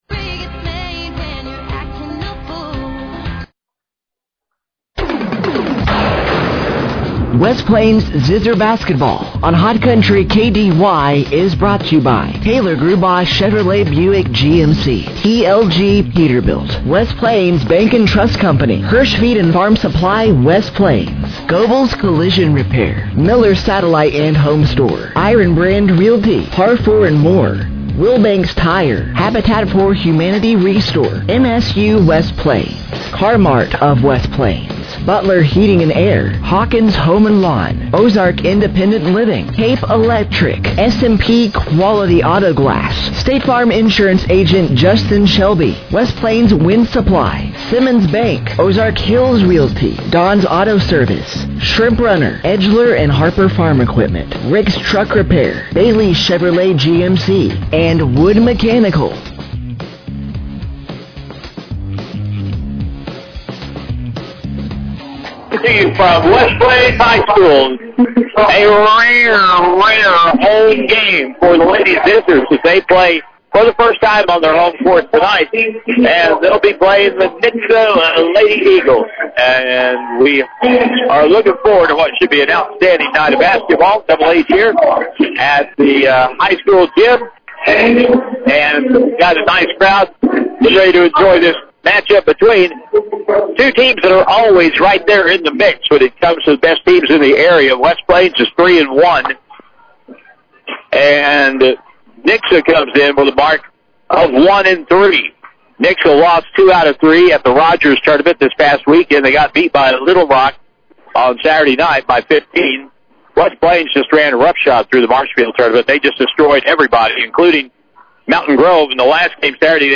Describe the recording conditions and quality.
The West Plains Lady Zizzers played their home opener tonight versus the Nixa Lady Eagles.